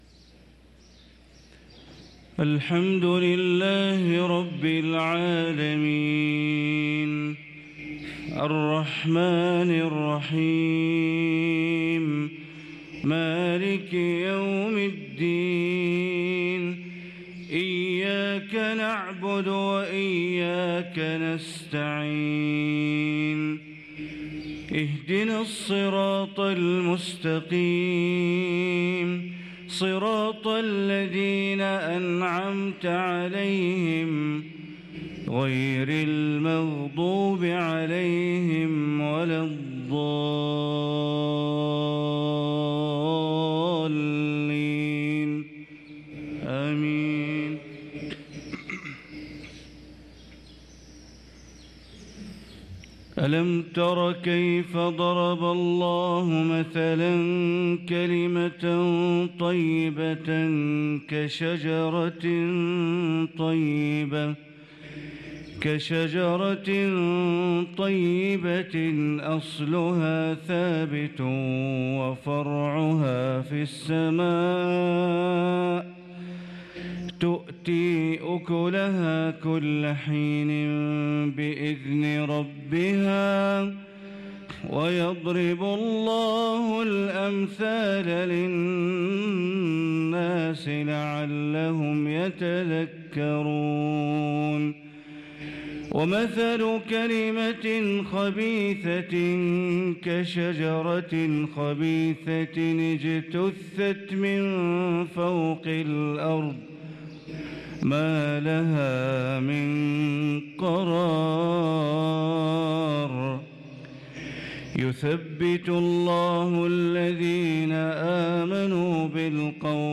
صلاة الفجر للقارئ بندر بليلة 6 جمادي الآخر 1445 هـ
تِلَاوَات الْحَرَمَيْن .